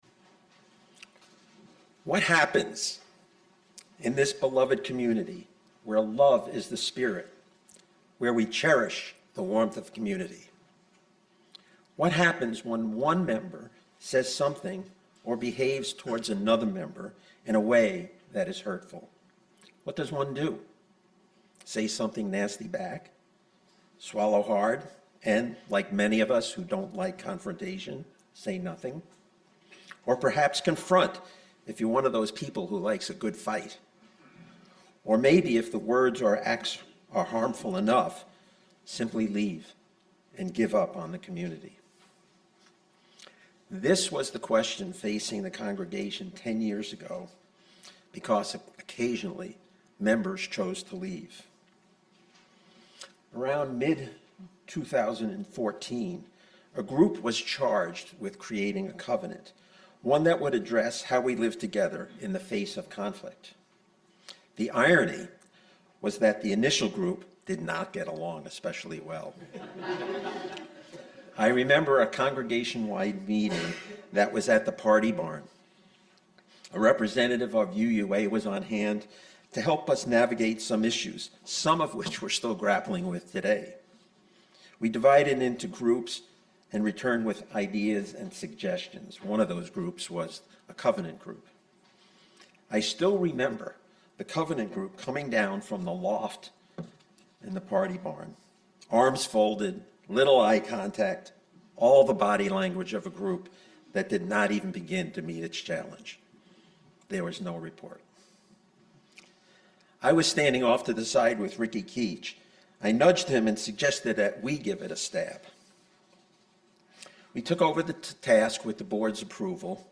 This sermon details the history and evolution of a religious community’s covenant, specifically focusing on how members navigate interpersonal conflict.